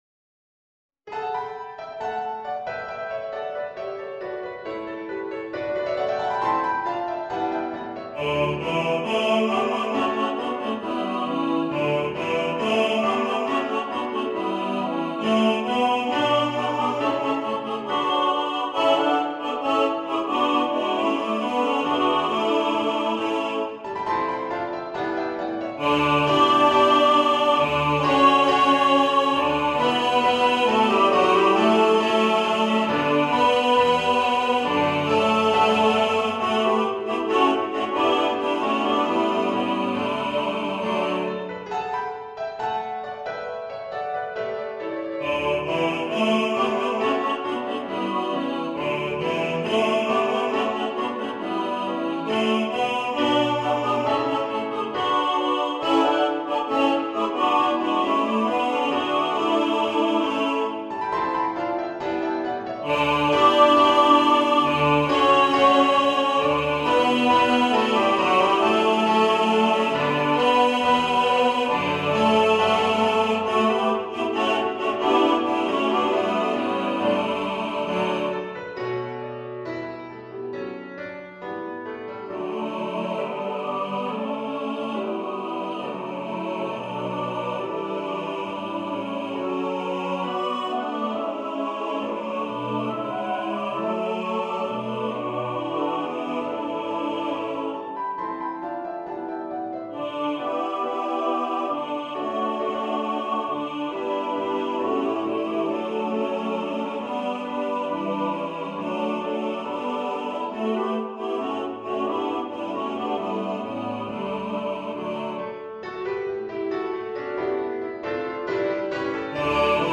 Star-Carol-All-Voices.mp3